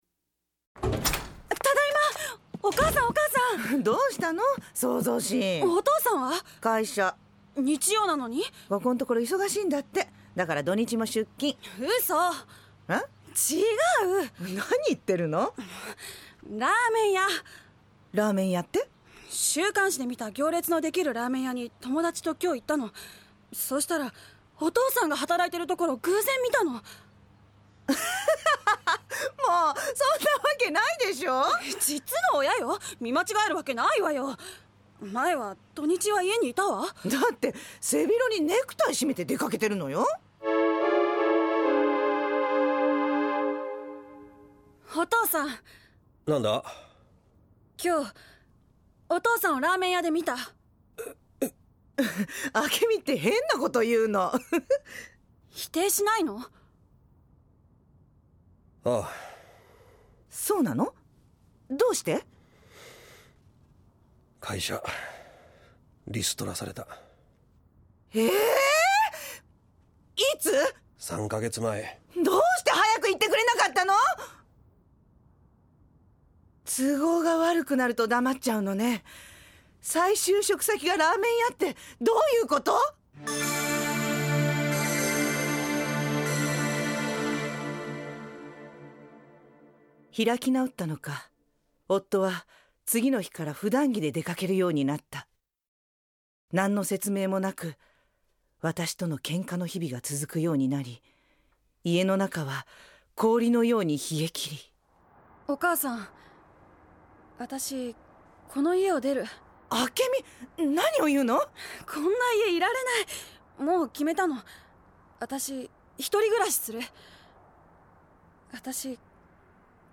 ●ラジオドラマ「ようお参りです」
・教会の先生（60歳・男性）